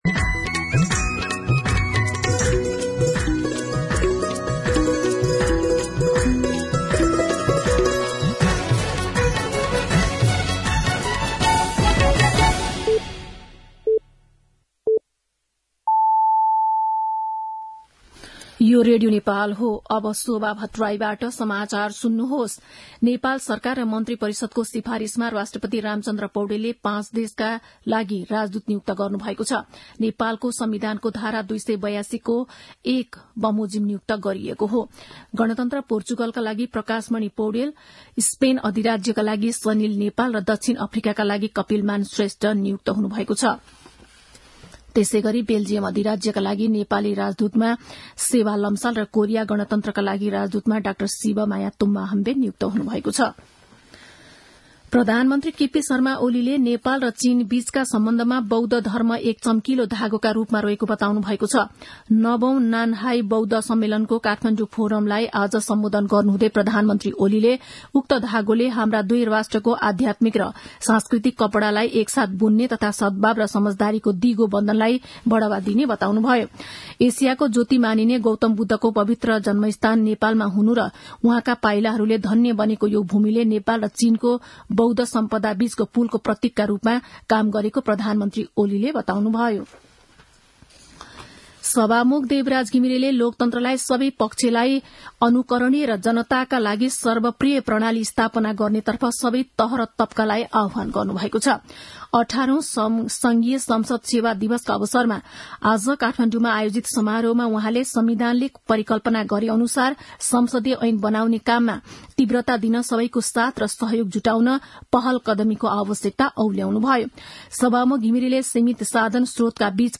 दिउँसो ४ बजेको नेपाली समाचार : २९ मंसिर , २०८१
4-pm-nepali-news-1.mp3